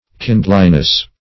Kindliness \Kind"li*ness\, n.